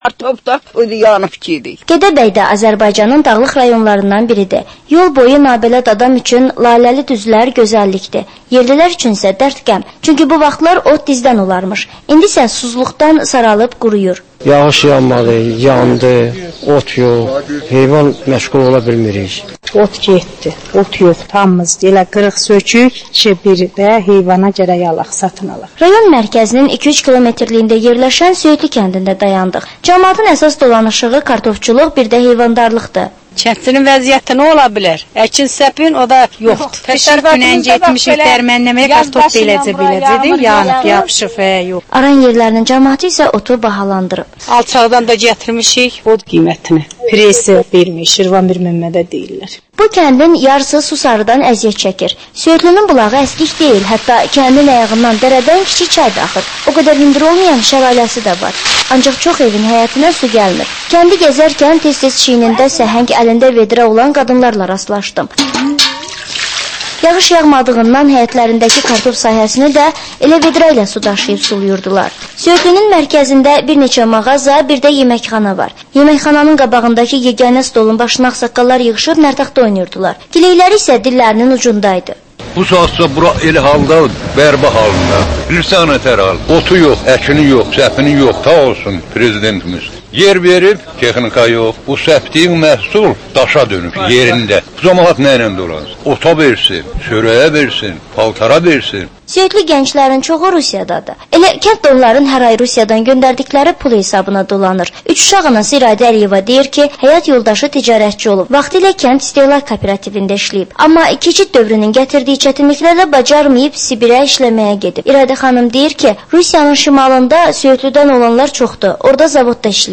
Azərbaycan Şəkilləri: Rayonlardan reportajlar.